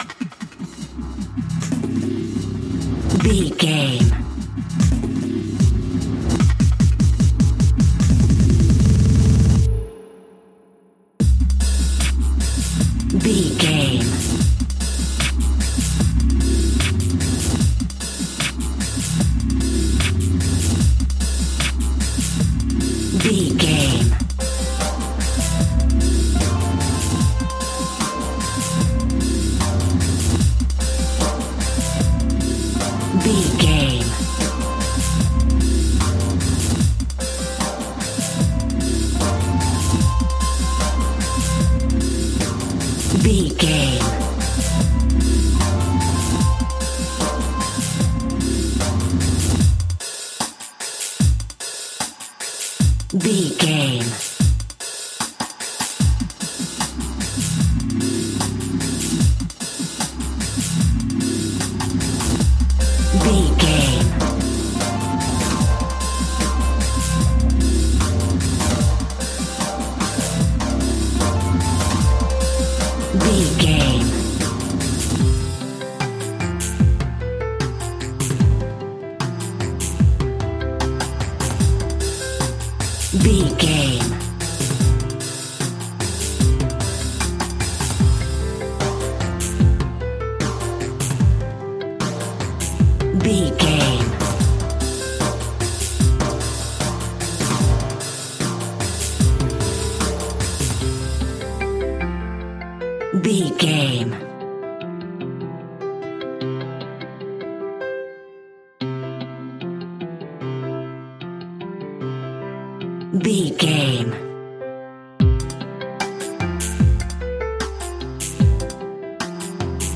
Dub Pop Industrial Music.
Ionian/Major
hip hop
chilled
laid back
hip hop drums
hip hop synths
piano
hip hop pads